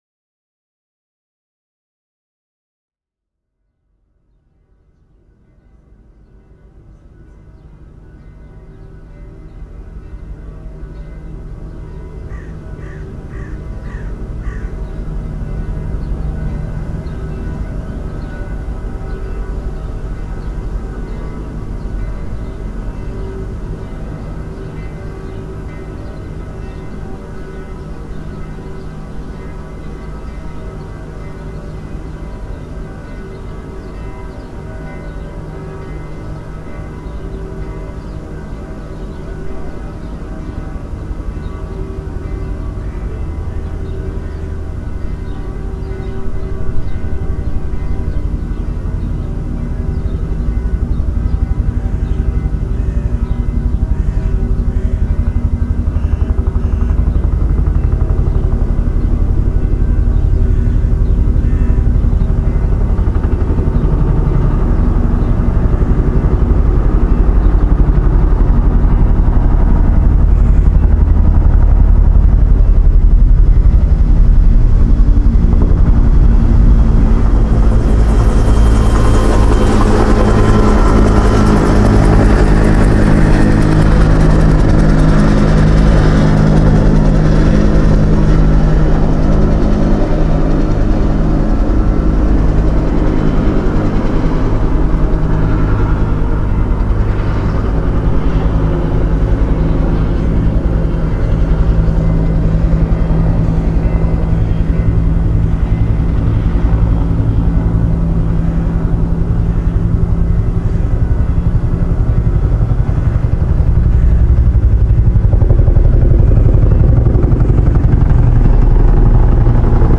This field recording is one collage of sounds that are conceptually symbolizing social and political events of the past of this particular place.
Recording was made from the balcony at the 12 floor of the building; I recorded with TASCAM DR1, the helicopter flayed ten meters from the microphone and created by that this nice multidimensional approaching sound picture."